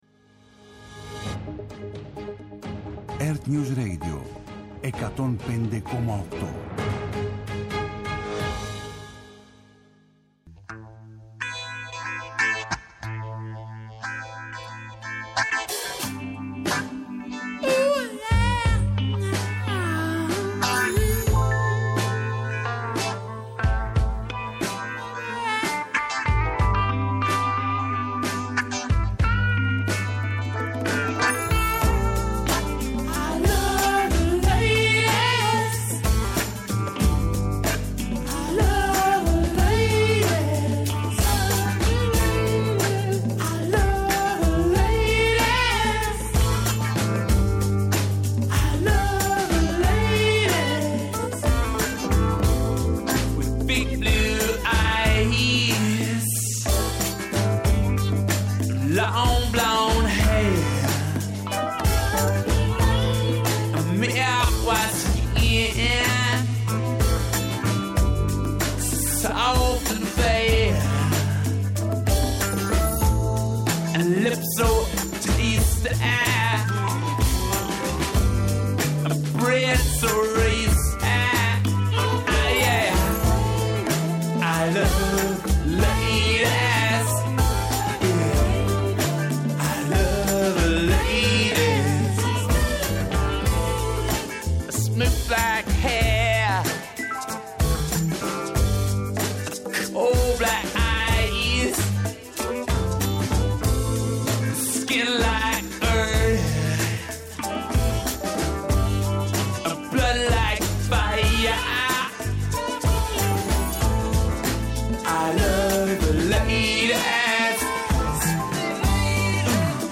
Εκπομπή επικαιρότητας